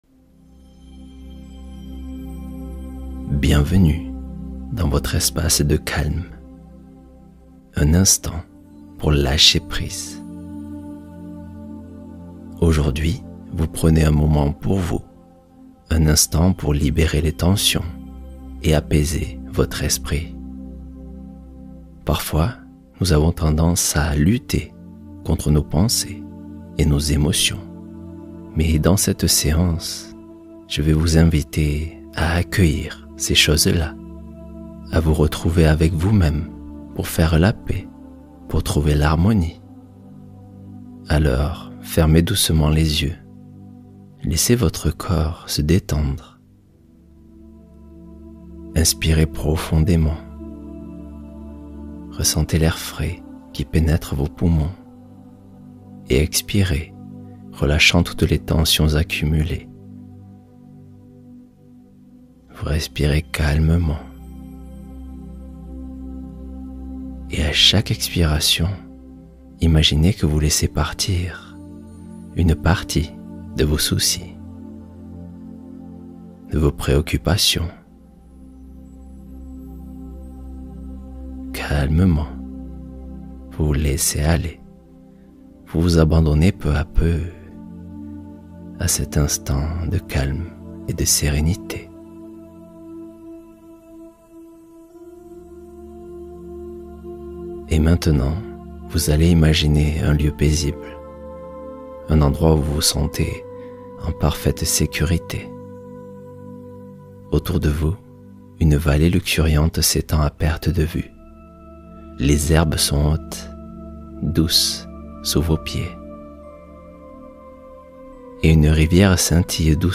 Force intérieure éveillée — Méditation puissante pour la confiance en soi